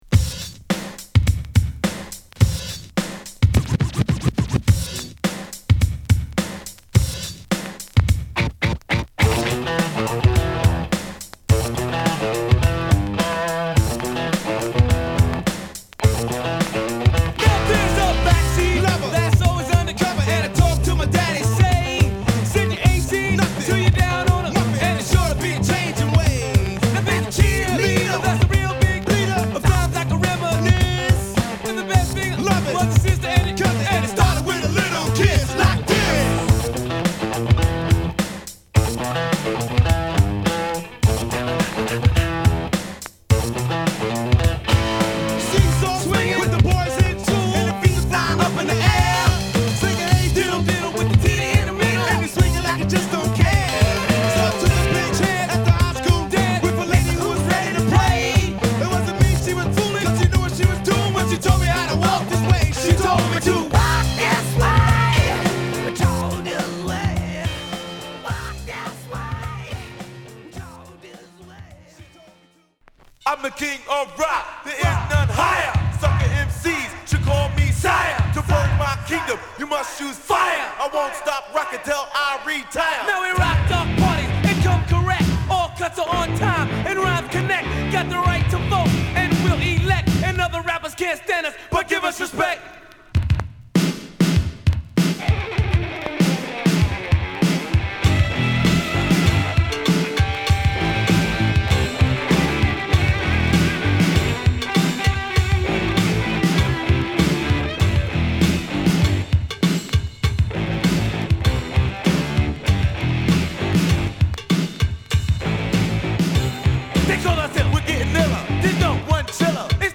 印象的なイントロのギターフレーズは今でもTVのジングルなどの様々なシーンでも使われていますね。